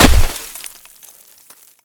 ground_place.ogg